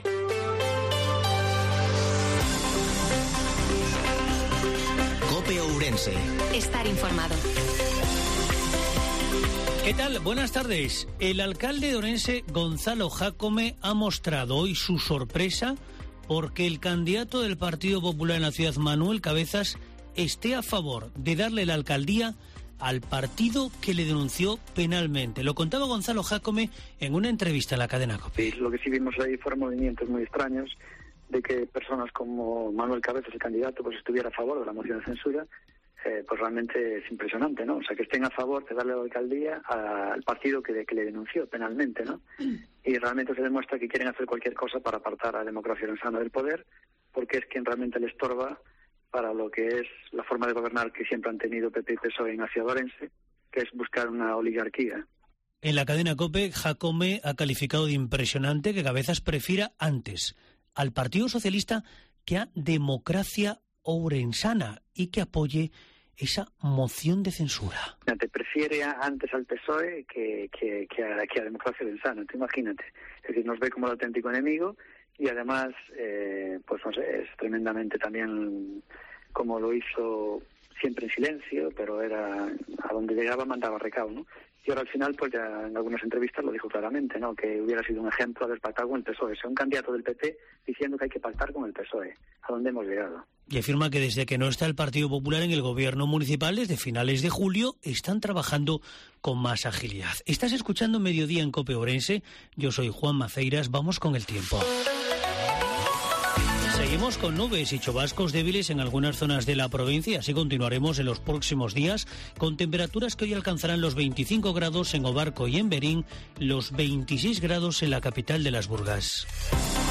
INFORMATIVO MEDIODIA COPE OURENSE-06/09/2022